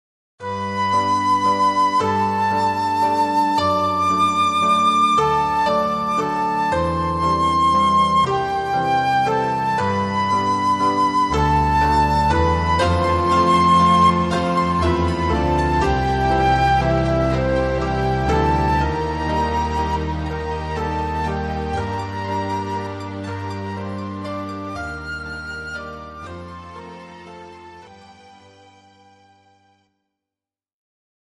Musique et claviers